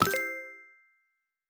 Special & Powerup (53).wav